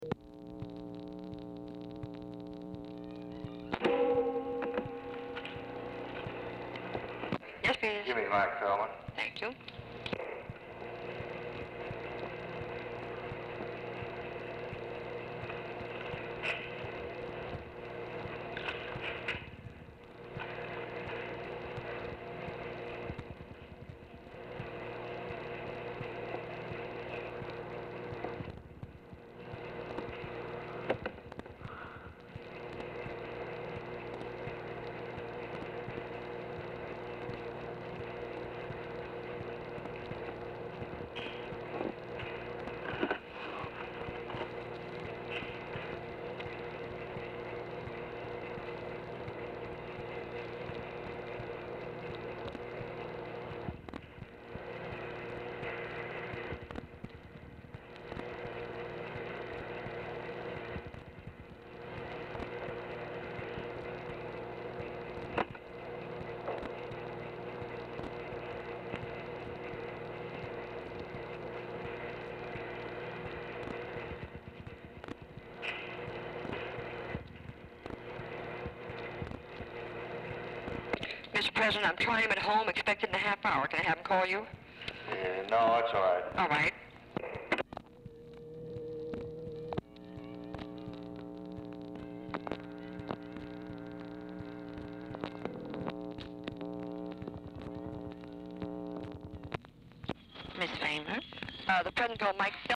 Telephone conversation # 5498, sound recording, LBJ and TELEPHONE OPERATOR, 9/5/1964, time unknown | Discover LBJ
LBJ ON HOLD DURING MOST OF RECORDING
Format Dictation belt